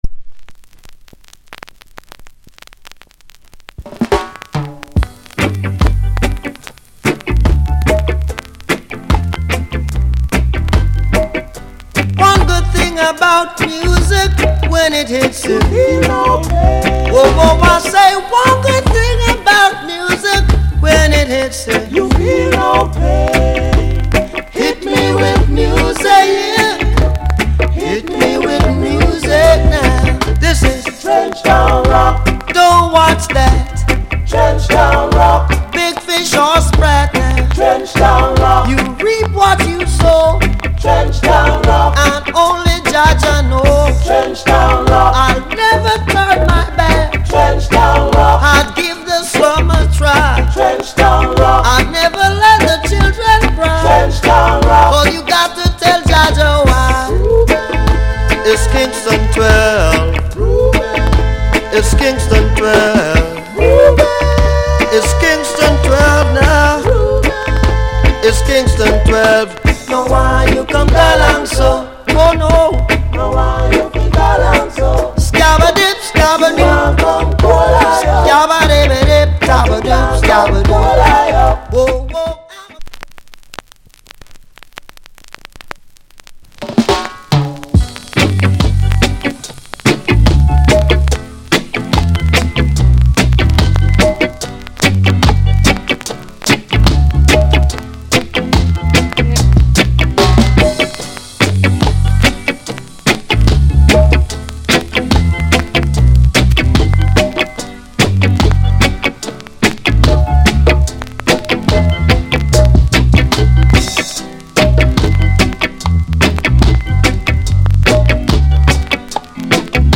Genre Reggae70sEarly / Male Vocal